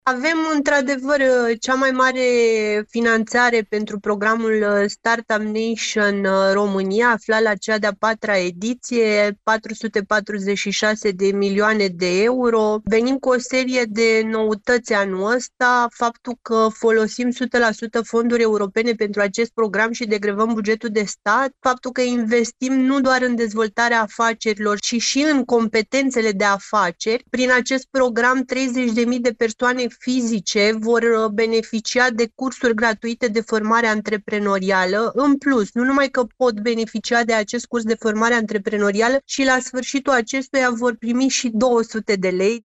i-a declarat într-un interviu colegului nostru